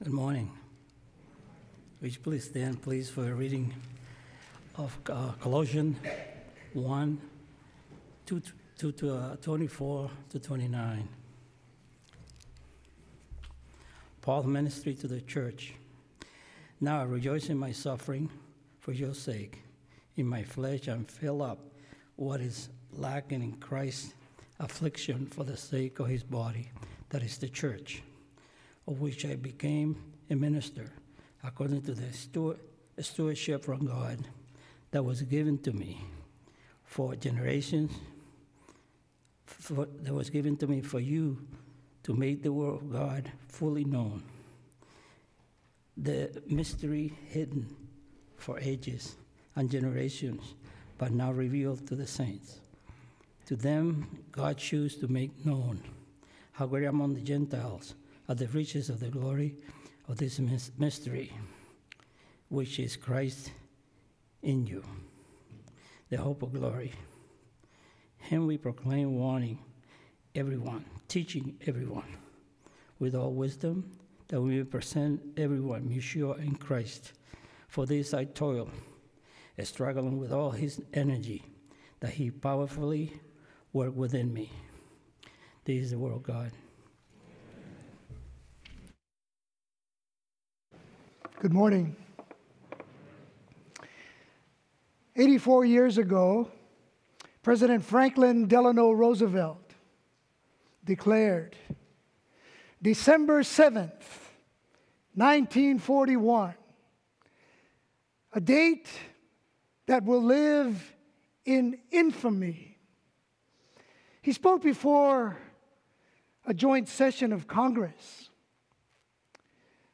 [INTERVIEW] Breastfeeding Made Easier